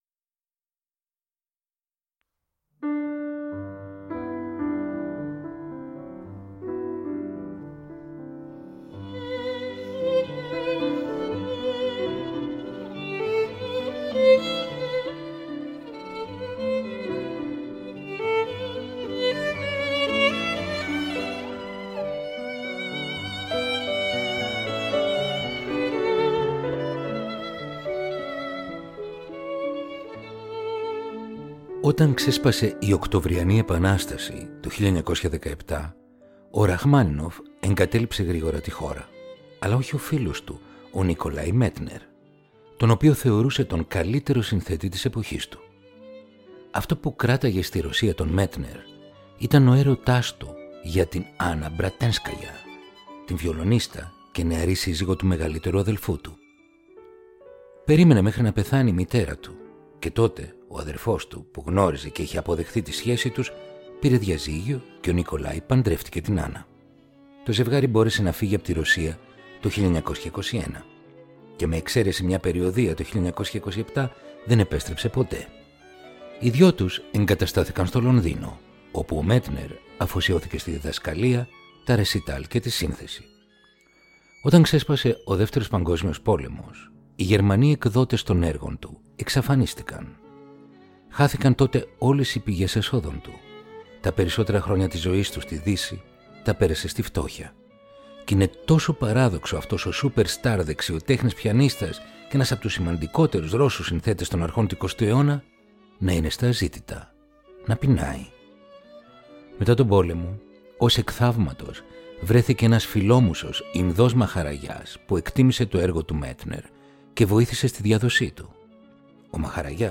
Ρομαντικά κοντσέρτα για πιάνο – Επεισόδιο 25ο